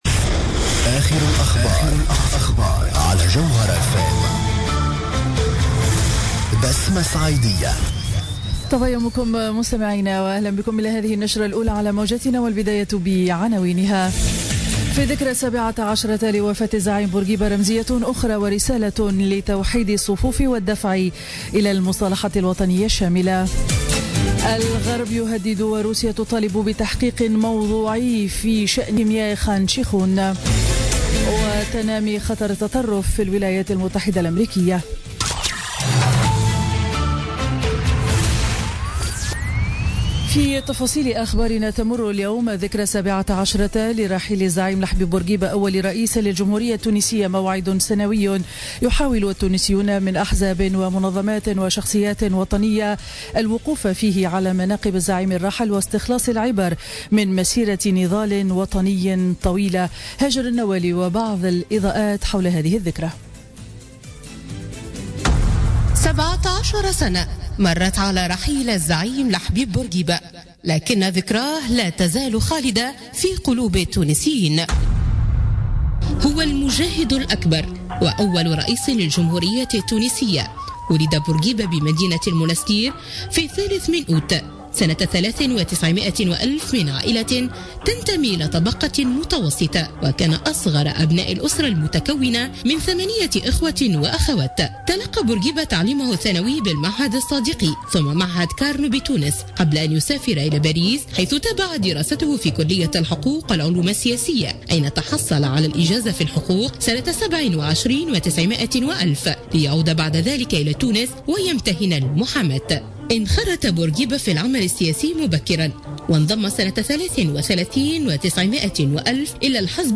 نشرة أخبار السابعة صباحا ليوم الخميس 6 أفريل 2017